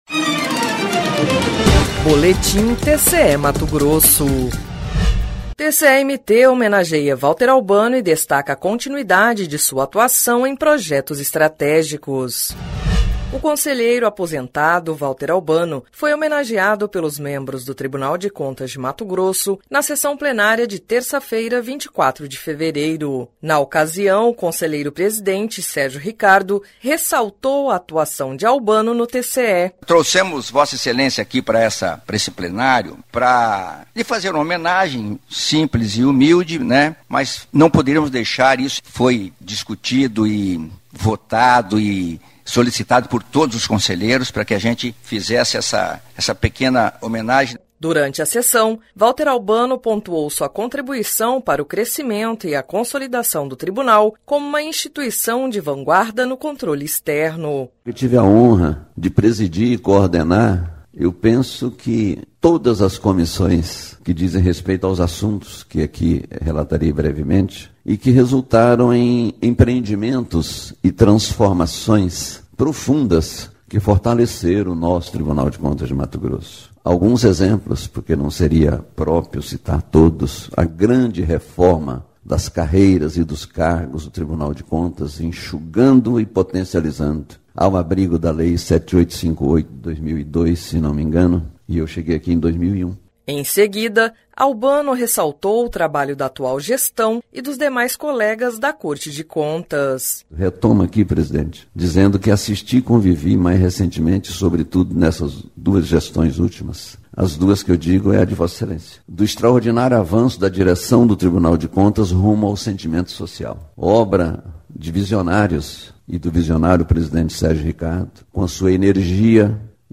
Sonora: Sérgio Ricardo – conselheiro-presidente do TCE-MT
Sonora: Valter Albano – conselheiro aposentado do TCE-MT
Sonora: Alisson Alencar – conselheiro do TCE-MT
Sonora: Campos Neto – conselheiro do TCE-MT
Sonora: William Brito Júnior - procurador-geral de contas
Sonora: Waldir Júlio Teis – conselheiro do TCE-MT